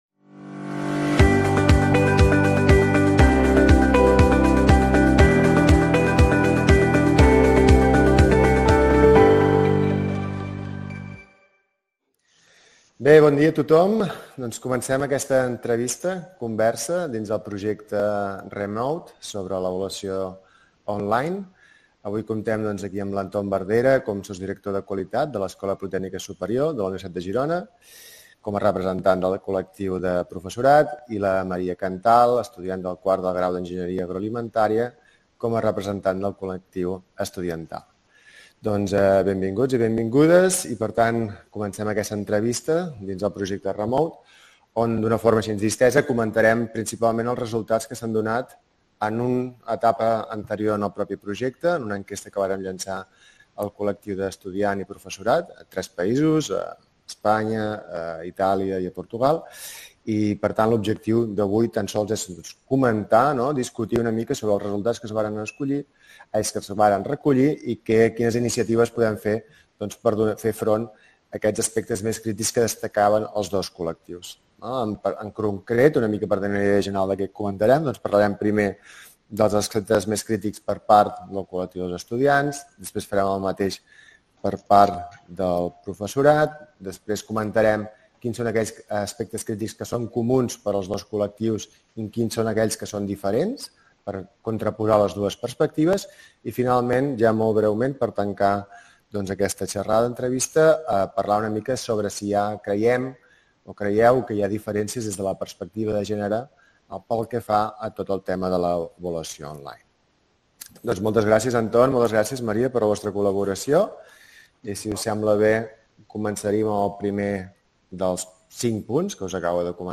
Entrevista-conversa